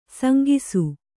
♪ sangisu